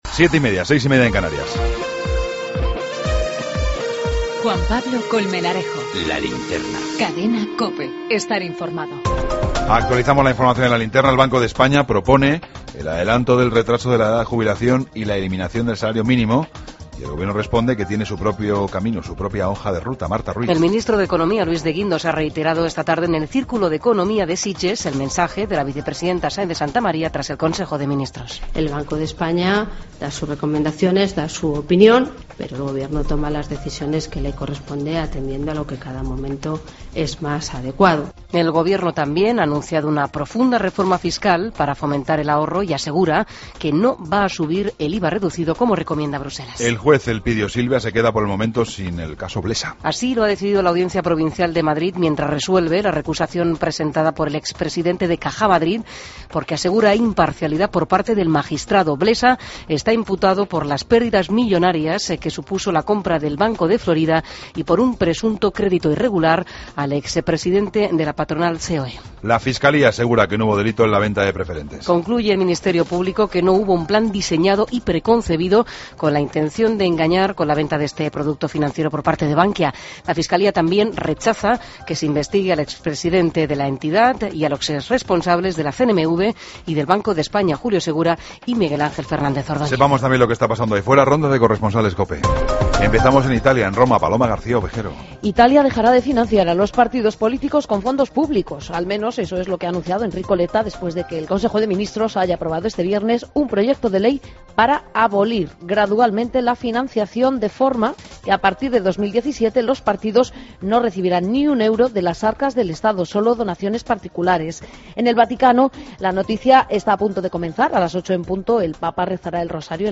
AUDIO: Toda la información con Juan Pablo Colmenarejo. Ronda de corresponsales.